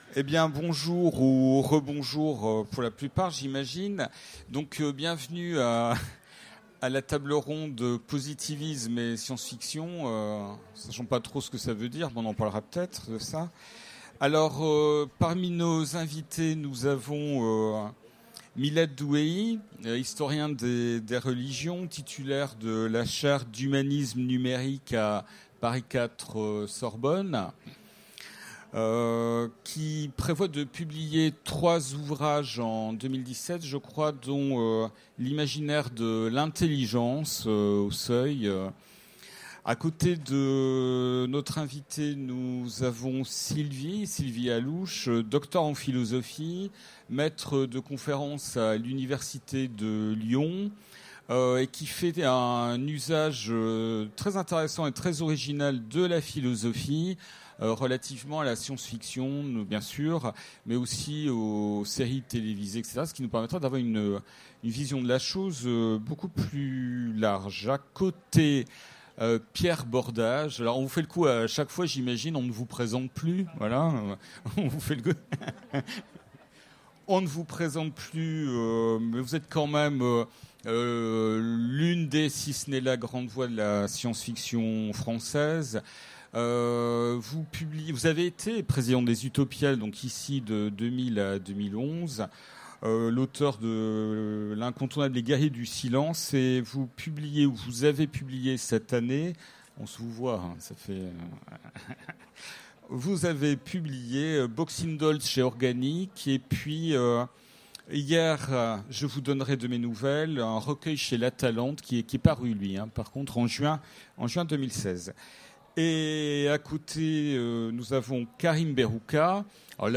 Utopiales 2016 : Conférence Positivisme et science-fiction